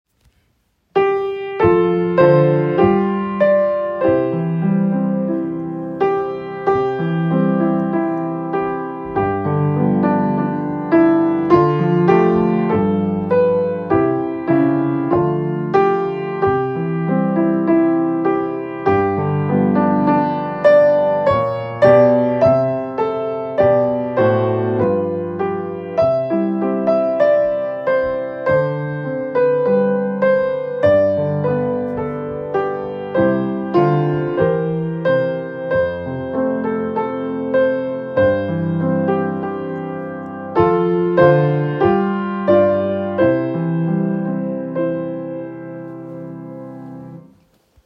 Audio: America the Beautiful - Accompaniment
America-the-Beautiful-accompaniment.m4a